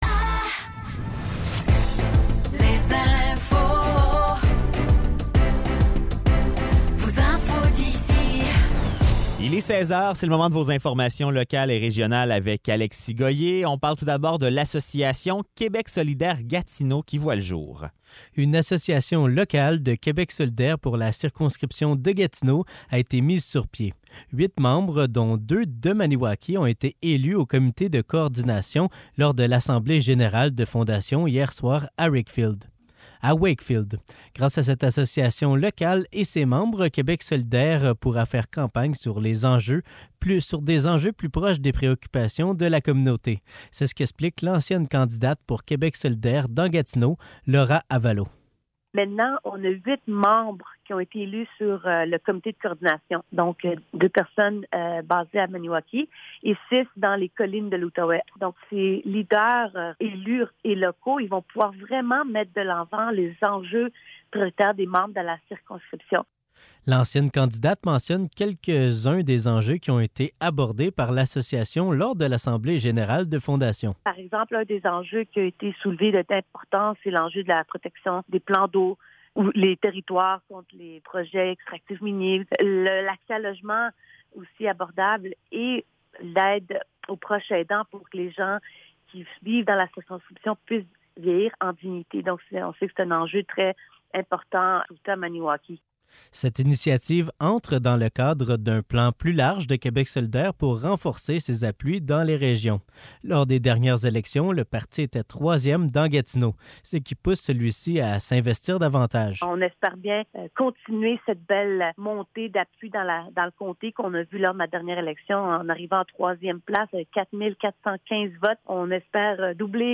Nouvelles locales - 16 octobre 2023 - 16 h